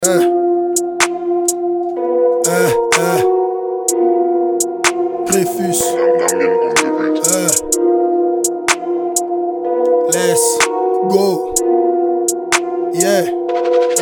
The handclaps are peaking about -4.6dBFS. The LUFS was -15 which is not bad at all.
The claps you are using are overriding everything.
FWIW, this is your file, with a heavy limiter to tame the peaks, then boosted.